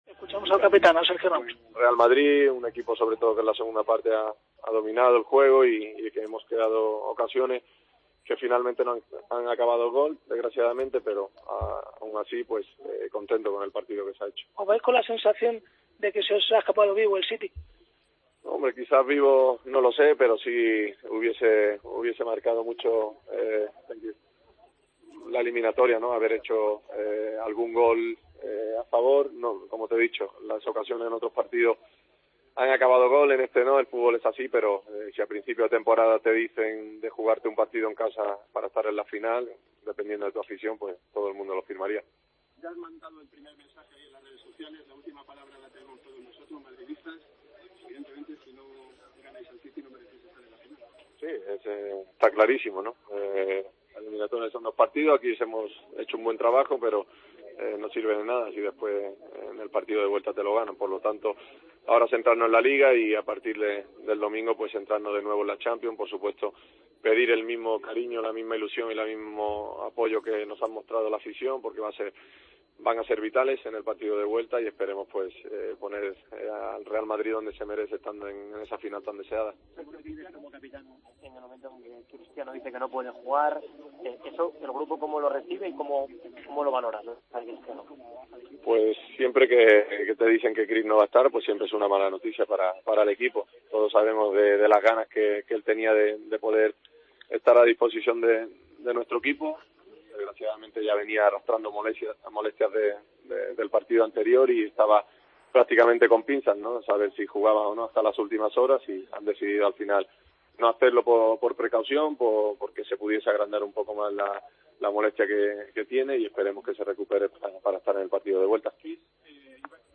"Todo el mundo firmaría jugarse el pase a la final en casa con su afición. Nos centraremos en lograr la clasificación esté Cristiano o no. No ha jugado por precaución", valoró el capitán del Real Madrid, en zona mixta, tras el empate sin goles en Manchester.